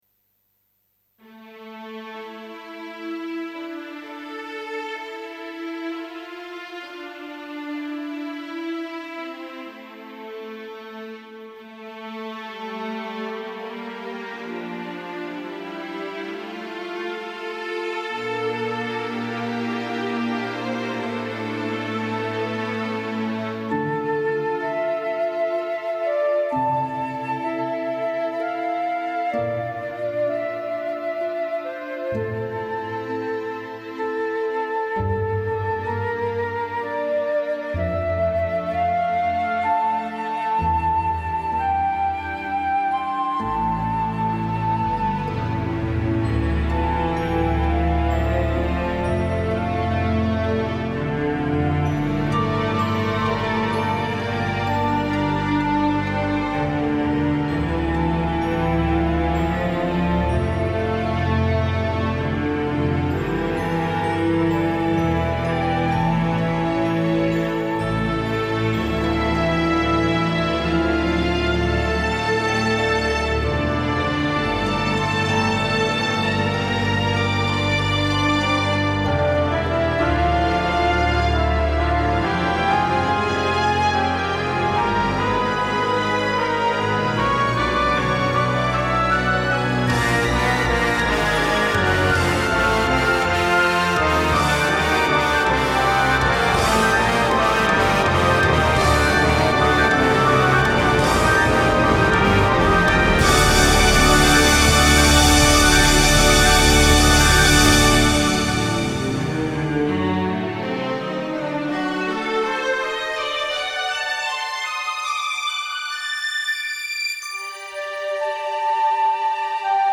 Played by Solo Orchestra